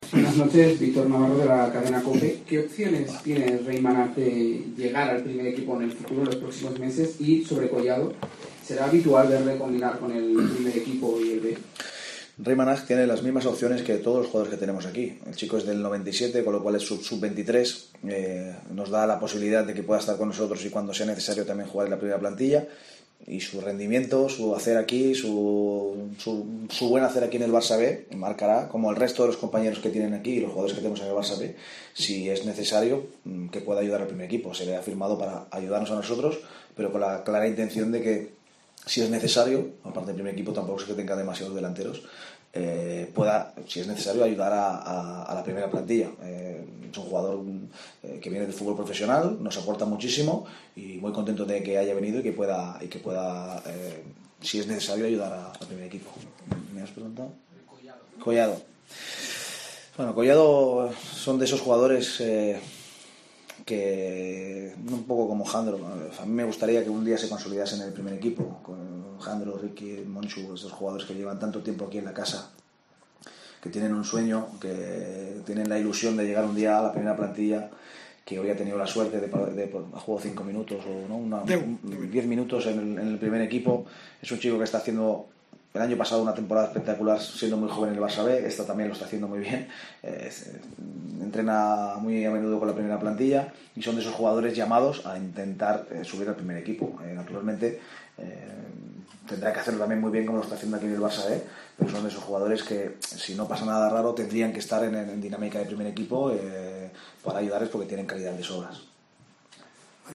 respondiendo a las preguntas de la Cadena COPE en la rueda de prensa posterior al Barça B-Ejea